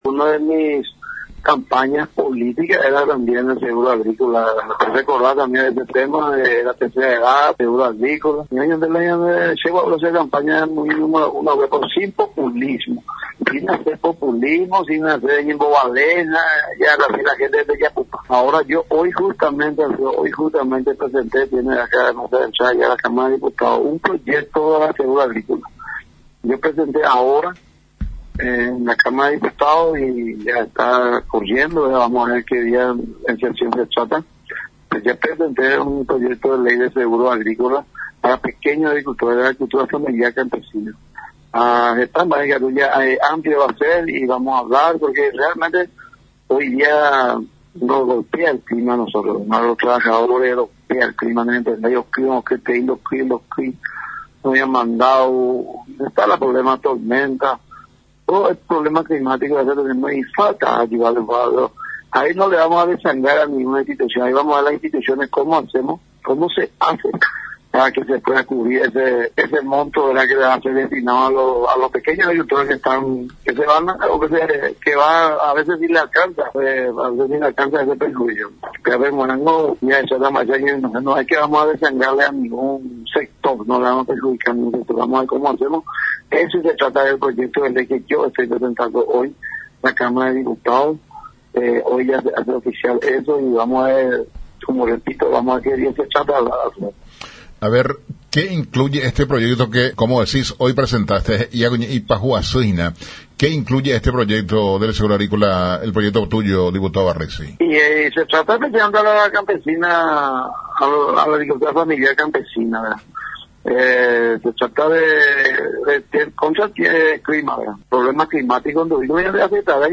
EDITADO-6-JORGE-BARRESI-DIPUTADO.mp3